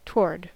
Ääntäminen
IPA: /ʊm/